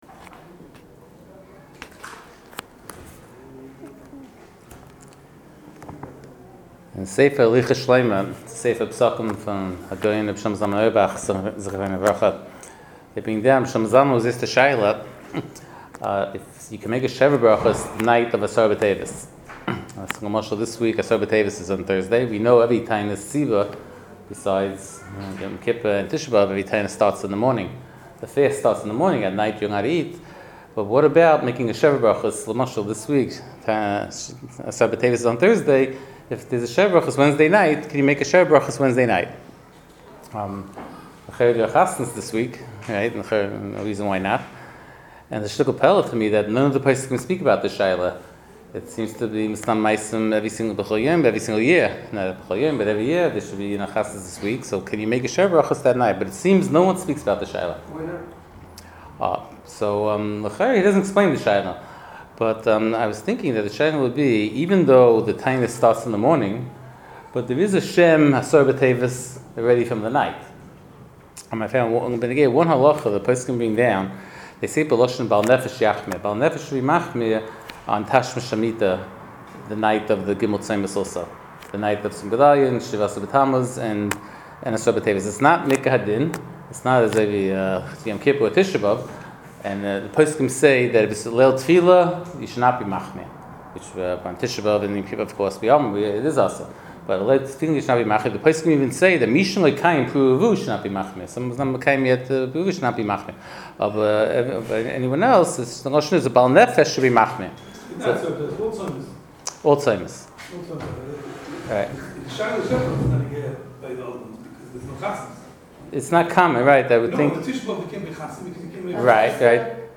Shiur provided courtesy of Madison Art Shop.